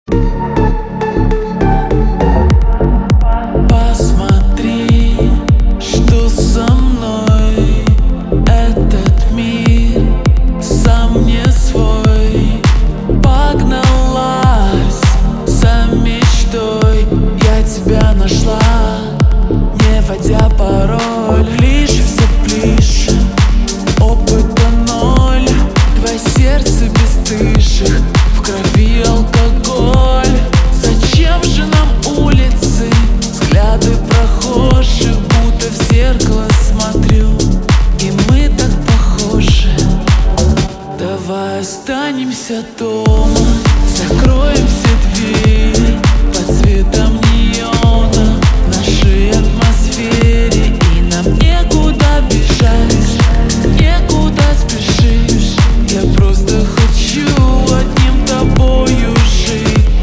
поп
remix
deep house
медленные